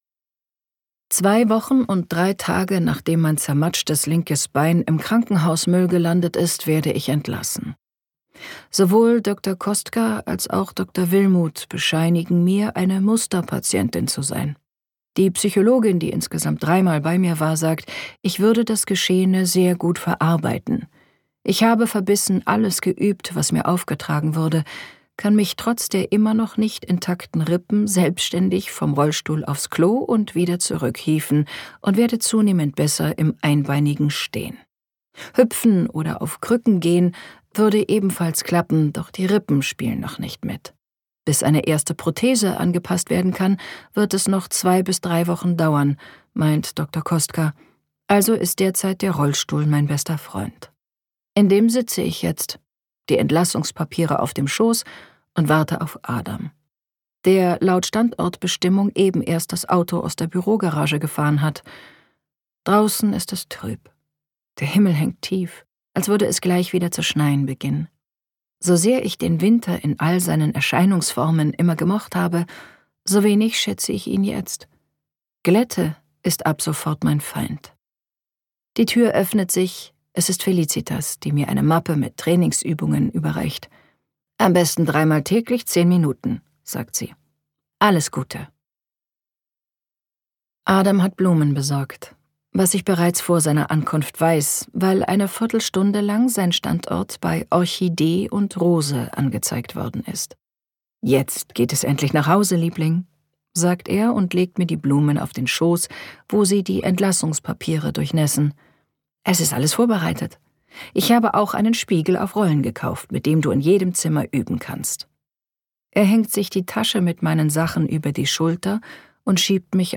Das Signal Gelesen von: Tessa Mittelstaedt